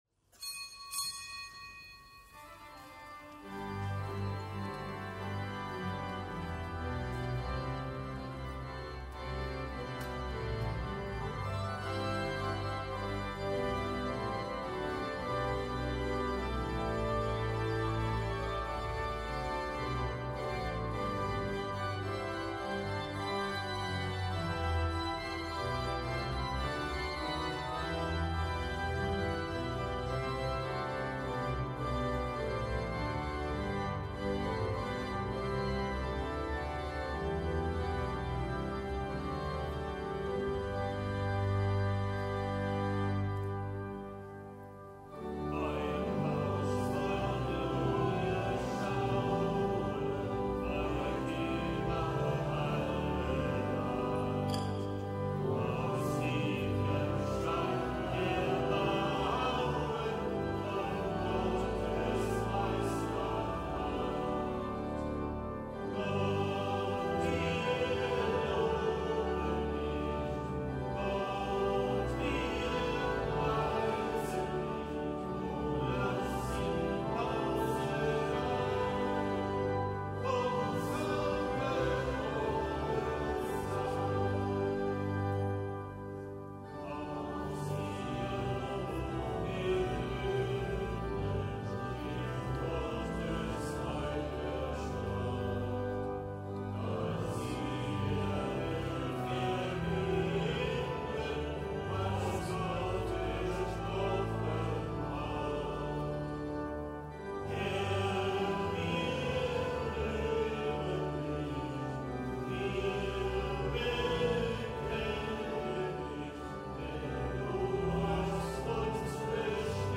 Kapitelsmesse am Hochfest Weihe der Kölner Domkirche
Kapitelsmesse aus dem Kölner Dom am Hochfest Weihe der Kölner Domkirche.